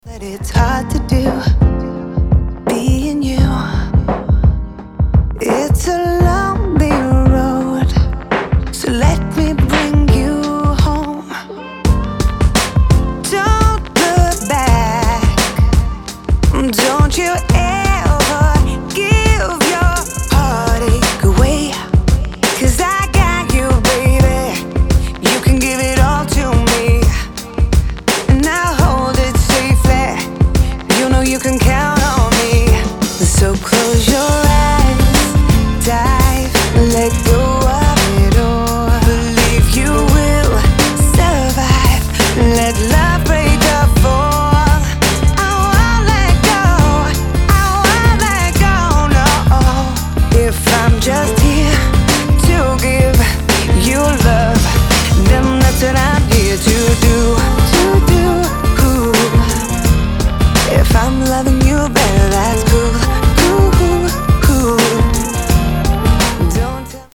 • Качество: 320, Stereo
поп
dance
vocal